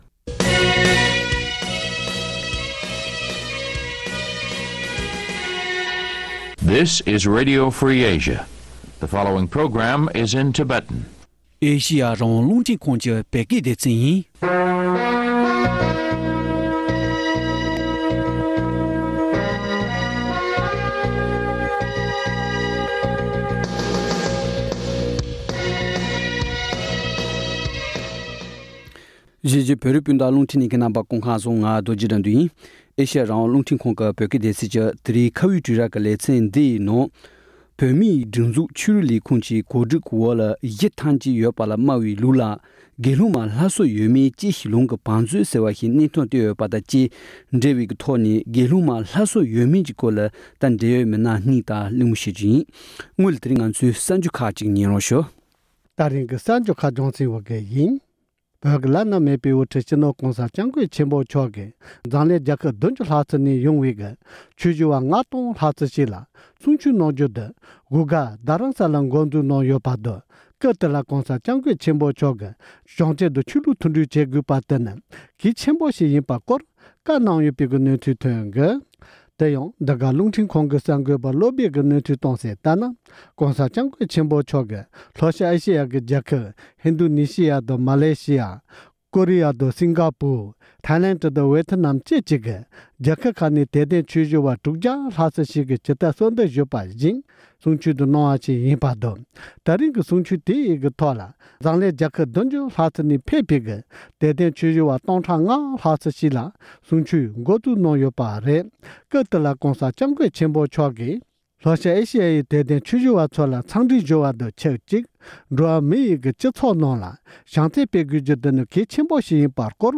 བཅའ་འདྲི་བྱེད་པ་ཞིག་ཉན་རོགས་ཞུ༎